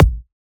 edm-kick-13.wav